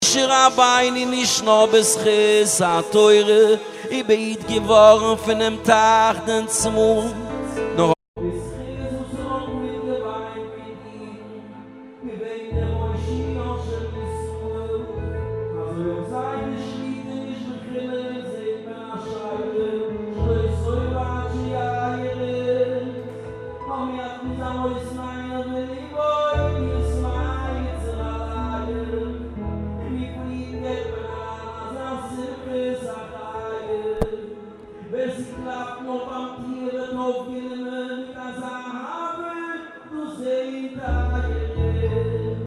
מישהו שלח לי לעריכה זה נשמע עם רוורב חזק או שהכבל לא הוכנס טוב יש דרך לתקן?